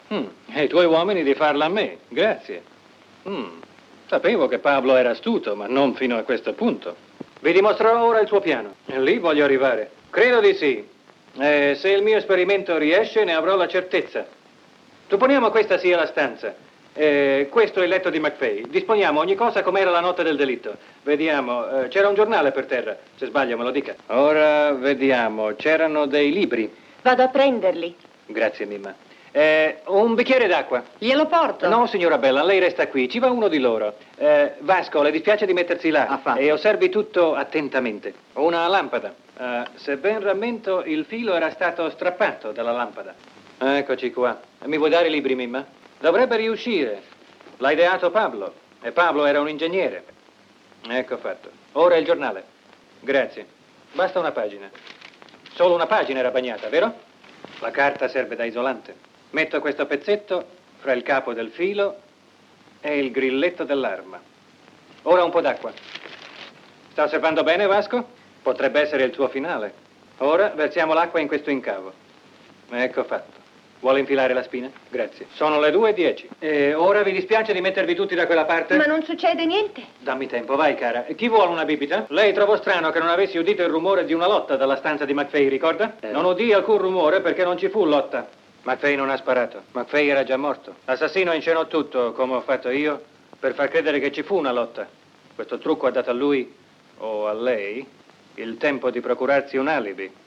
nel film "Si riparla dell'uomo ombra", in cui doppia William Powell.